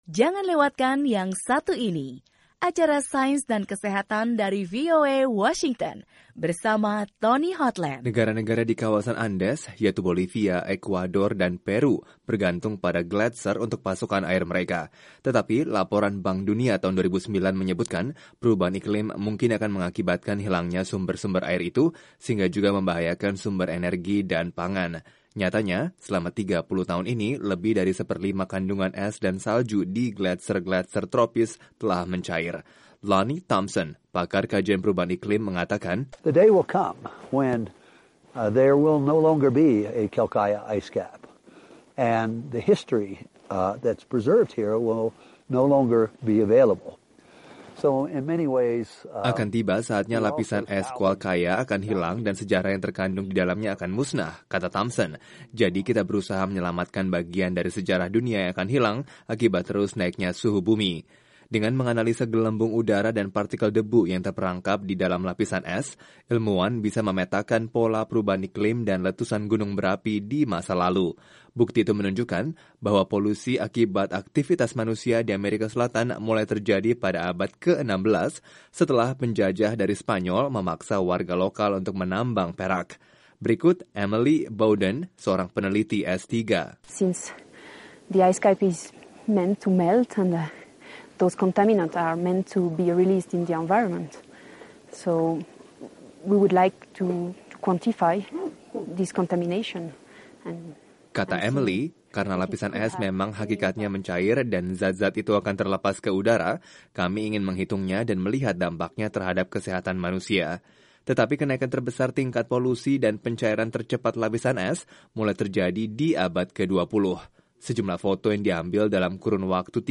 Lapisan es juga terdapat di pegunungan kawasan tropis, termasuk di puncak pegunungan Andes di Peru. Tetapi lapisan es itu terus mencair dengan cepat sehingga para ilmuwan mengumpulkan sampel guna mempelajari perubahan iklim. Laporan VOA